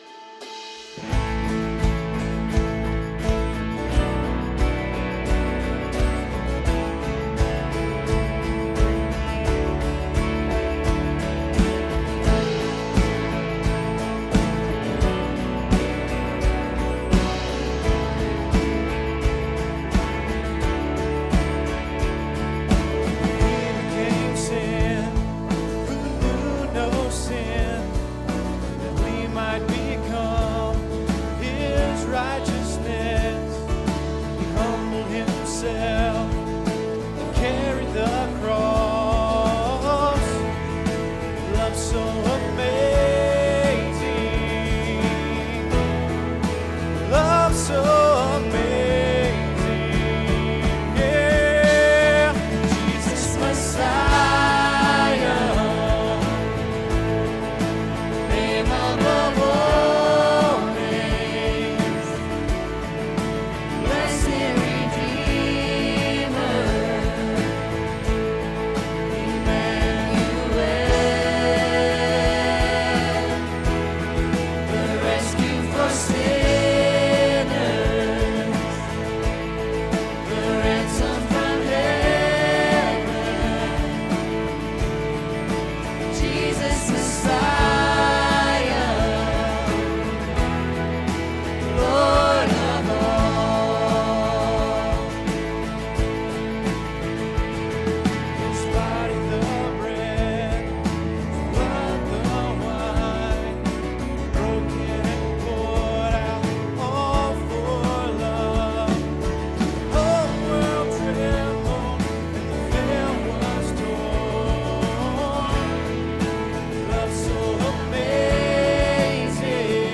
Sunday sermon on Matthew 7:7-11.